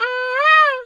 bird_peacock_squawk_12.wav